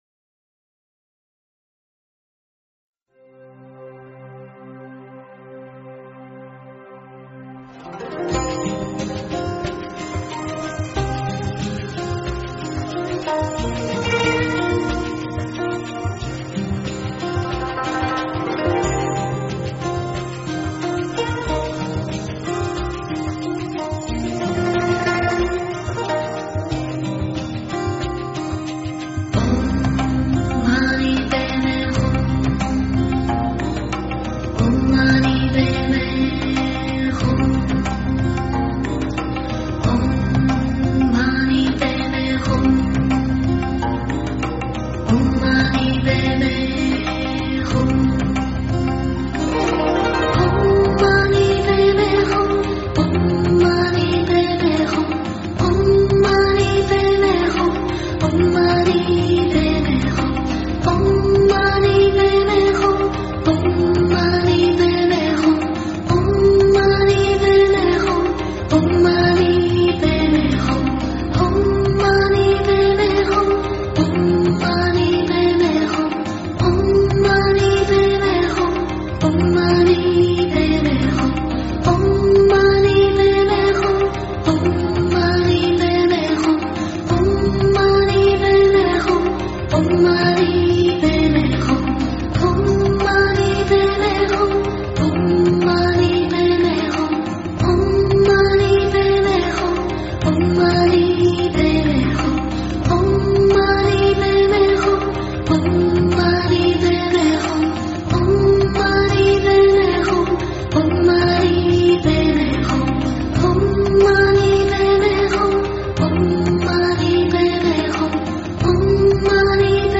六字心经--佛经音乐 真言 六字心经--佛经音乐 点我： 标签: 佛音 真言 佛教音乐 返回列表 上一篇： 日光菩萨陀罗尼和月光菩萨陀罗尼--海涛法师 下一篇： 给胎宝宝讲因果故事--有声佛书 相关文章 敦珠法王上师祈请文--贝玛千贝仁波切 敦珠法王上师祈请文--贝玛千贝仁波切...